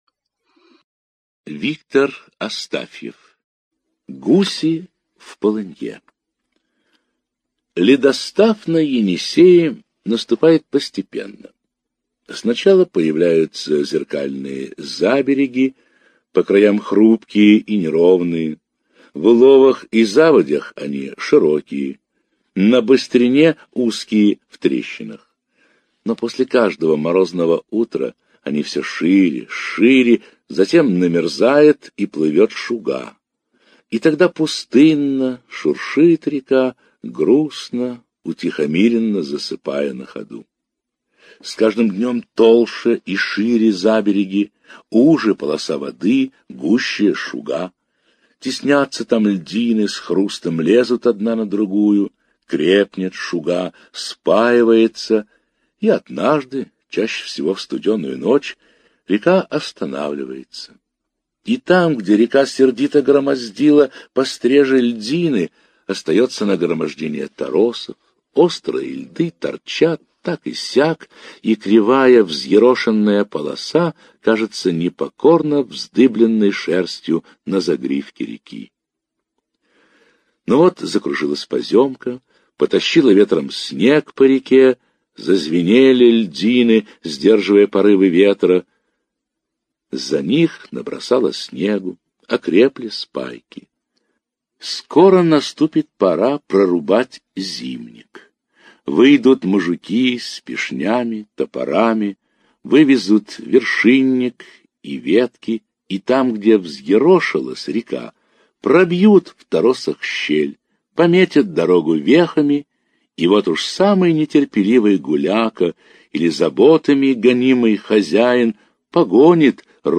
Гуси в полынье - аудио рассказ Астафьева В.П. Рассказ о том, как гусыня со своими гусятами попала в полынью на Енисее.